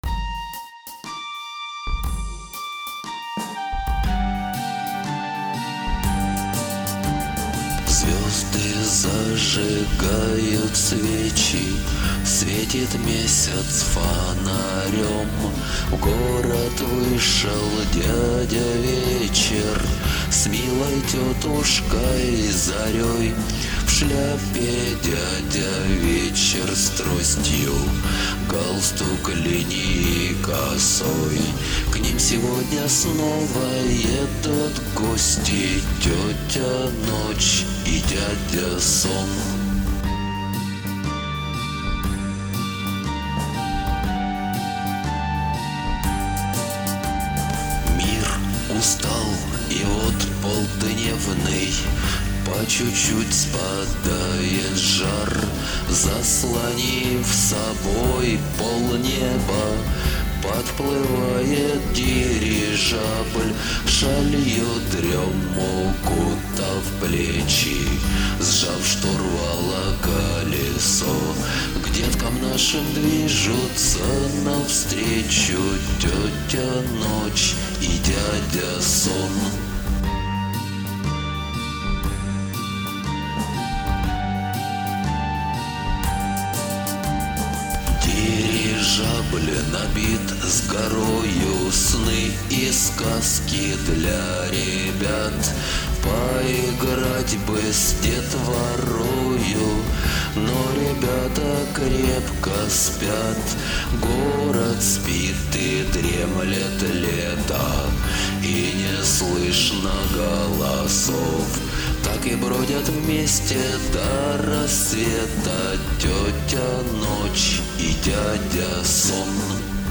Детская песенка.